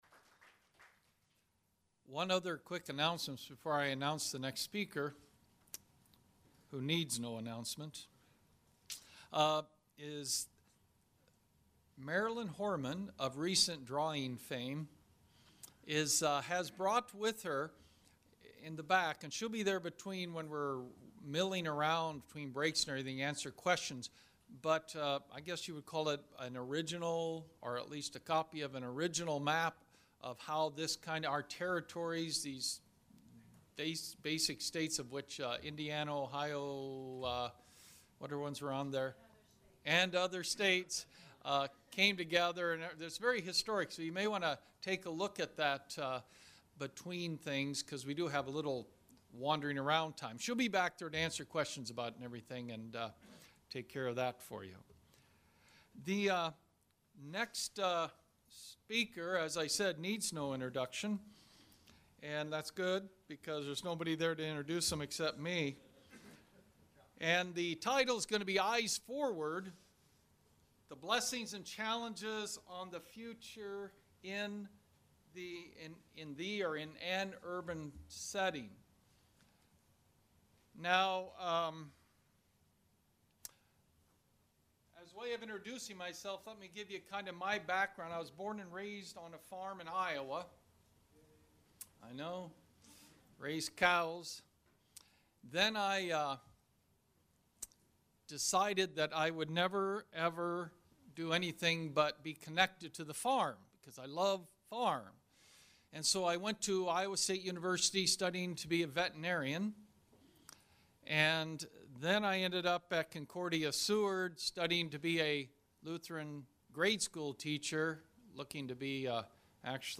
Additional Information: Collections This Item is in 1 Public Collection Urban Ministry Conference: In the World for Good 2013 by CTS This item is in 0 Private Collections Log in to manage and create your own collections.